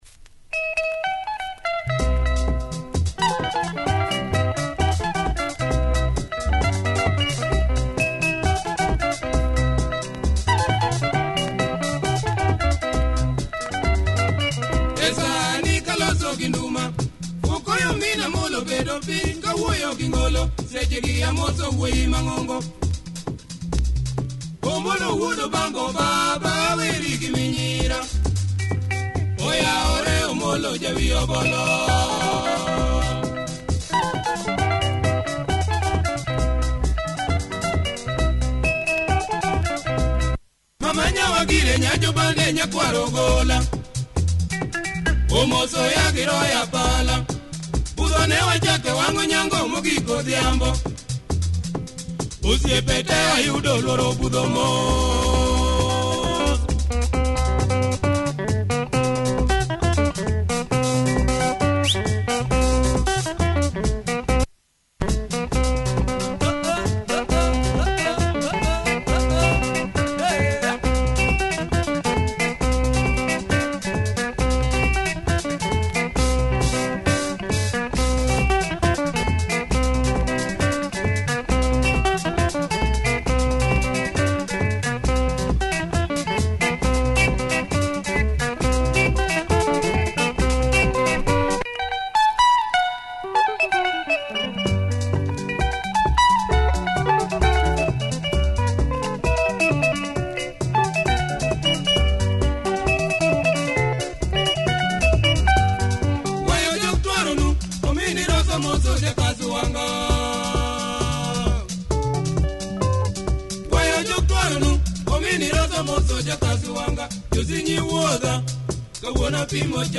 Nice drive in this LUO benga number, good production!